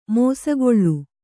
♪ mōsagoḷḷu